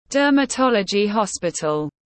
Dermatology hospital /ˌdɜː.məˈtɒl.ə.dʒi hɒs.pɪ.təl/